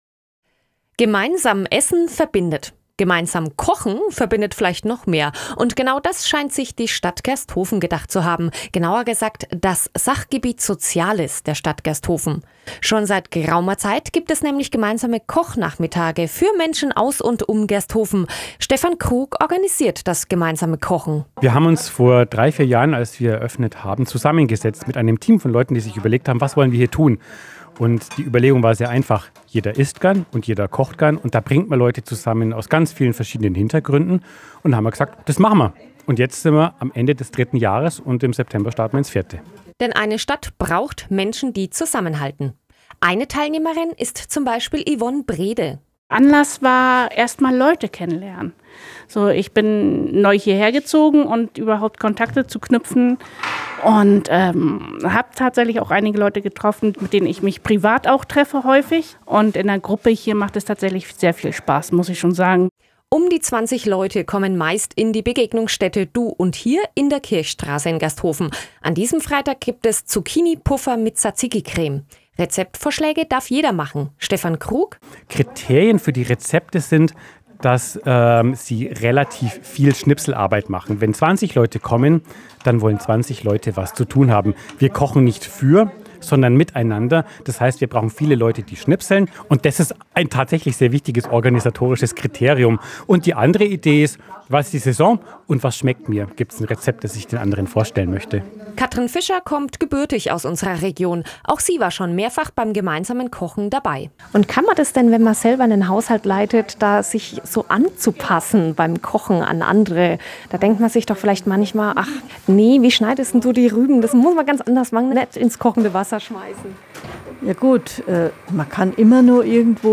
Radio Augsburg Berichterstattung, 7.10.2025